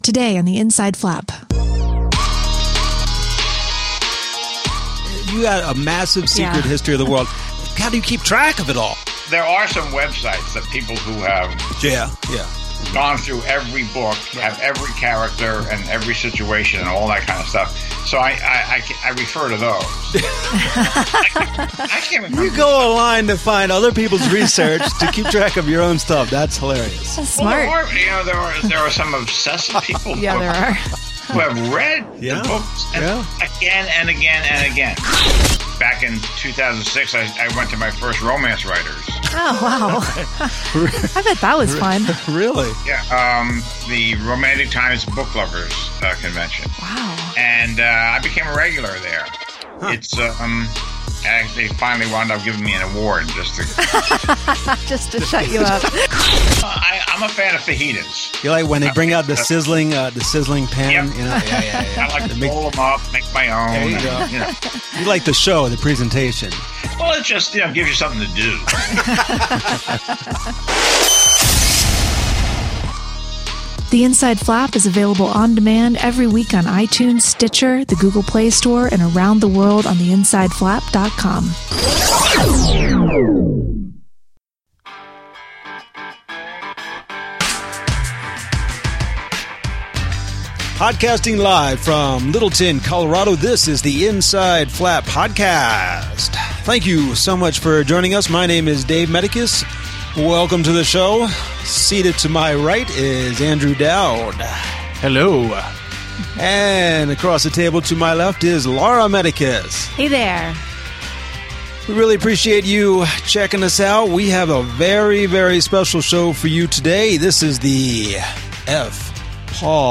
Ep. 25 F. Paul Wilson Interview and More!
The legendary F. Paul Wilson joins us this week! We recommend: Healer, Midnight Mass and Panacea all by the one and only F. Paul Wilson.